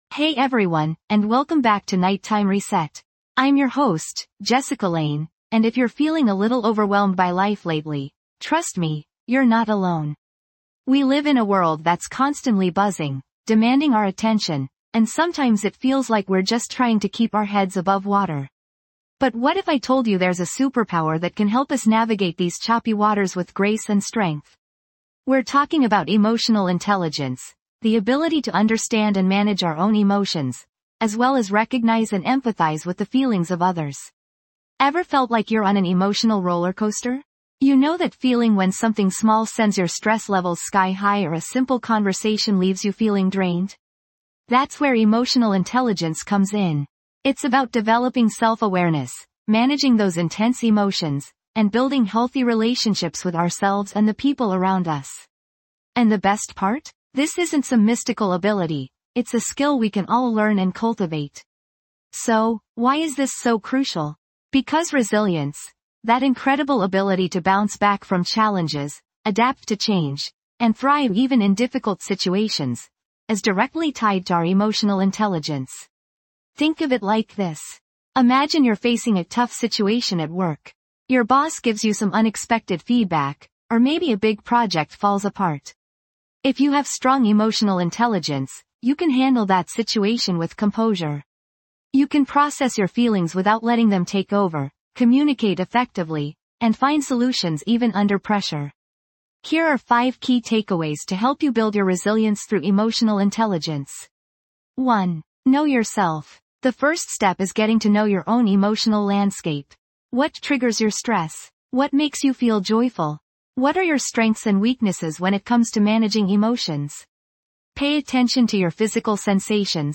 This podcast offers a nightly ritual designed to help you unwind, de-stress, and prepare for a peaceful night's rest. Through guided meditations, relaxing soundscapes, and practical sleep hygiene tips, we'll work together to improve your sleep quality, boost your mood, and enhance your mental well-being.